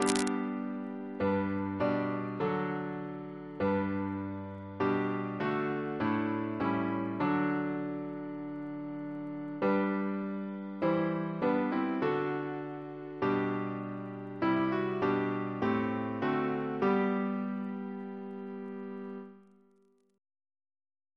Double chant in G Composer: Richard P. Goodenough (1775-1826) Reference psalters: ACP: 296; PP/SNCB: 203